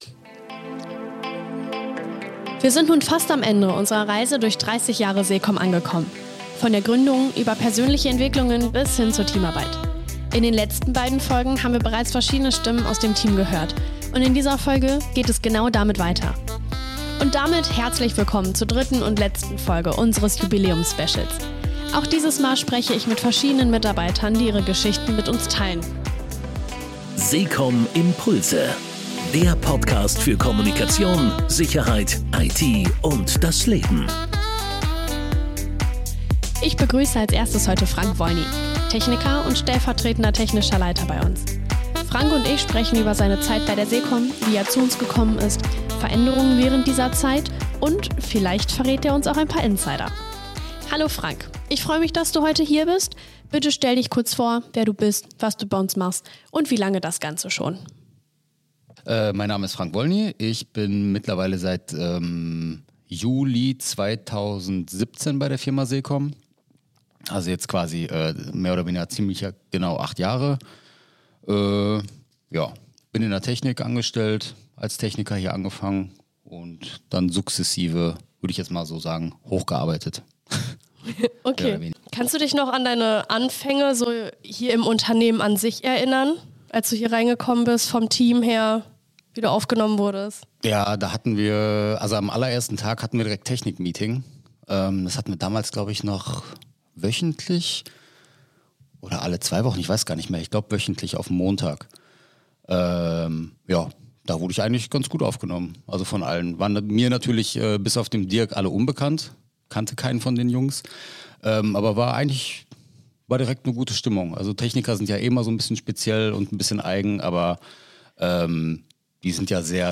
Die Jubiläumsreihe geht zu Ende. In der dritten und letzten Folge des Specials spreche ich mit weiteren vier Menschen der SEC-COM.